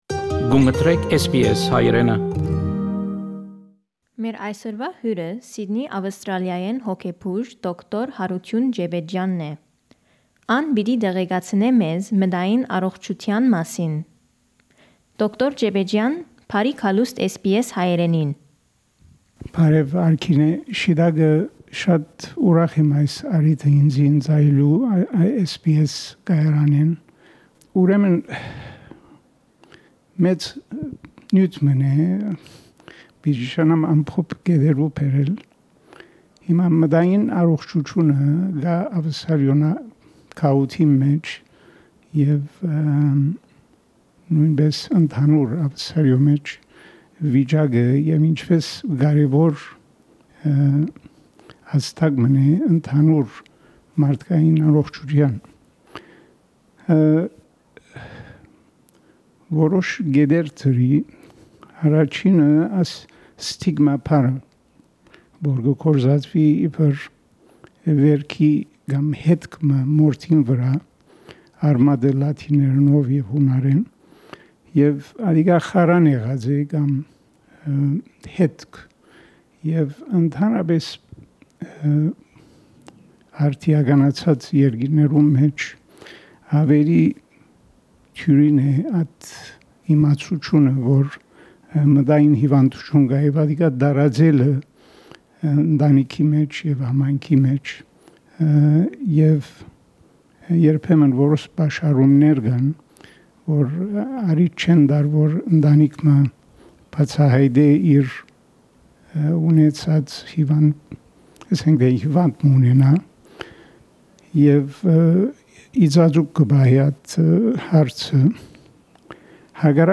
Interview with psychiatrist